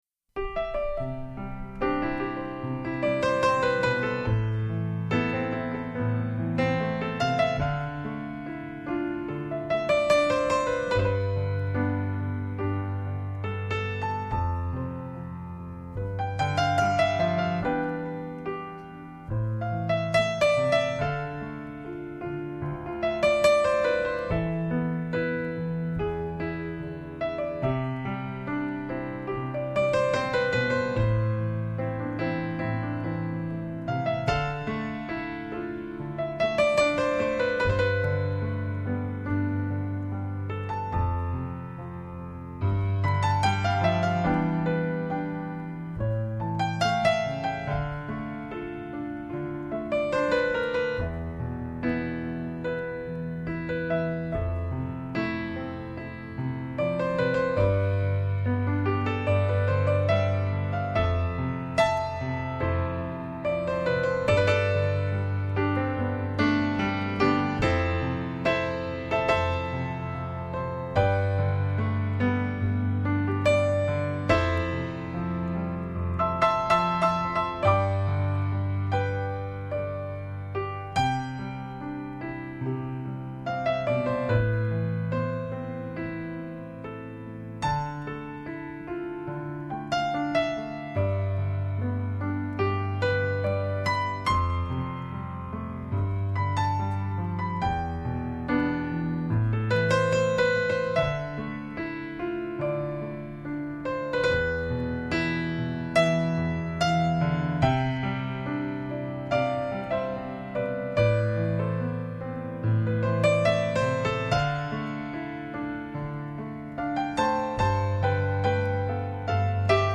歌曲类型：爵士乐，钢琴